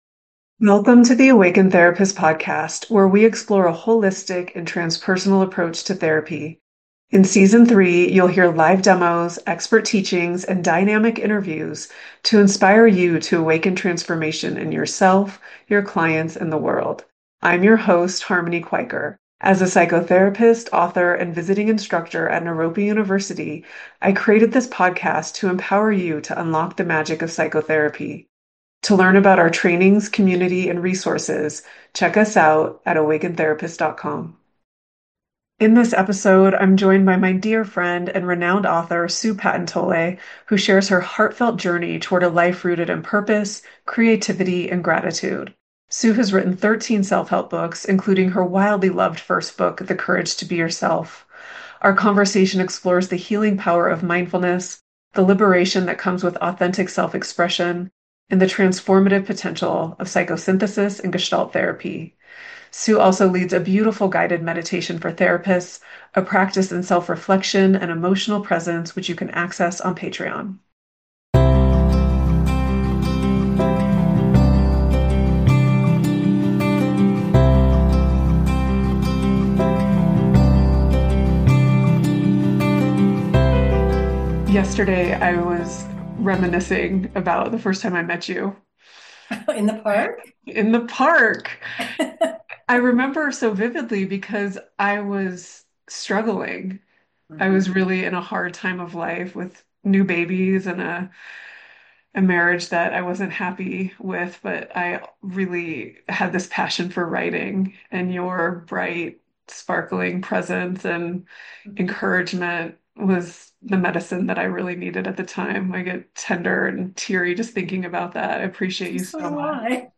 In this heartfelt conversation